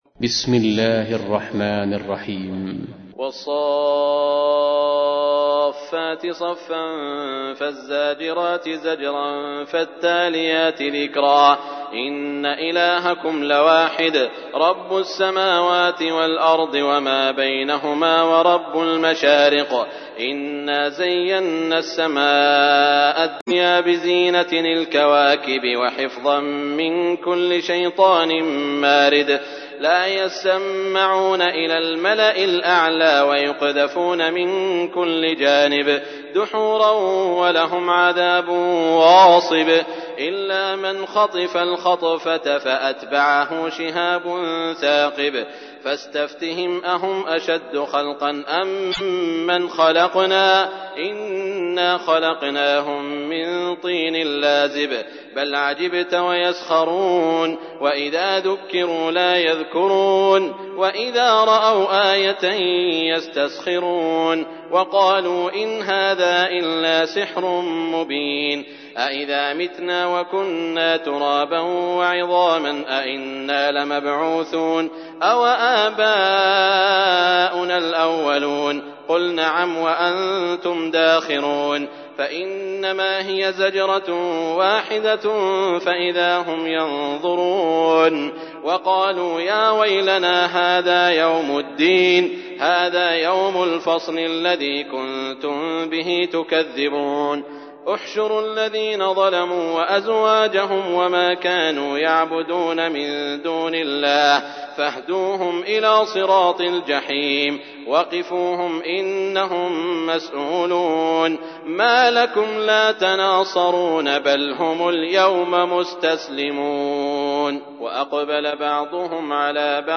تحميل : 37. سورة الصافات / القارئ سعود الشريم / القرآن الكريم / موقع يا حسين